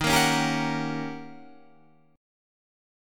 D#7#9 Chord